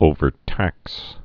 (ōvər-tăks)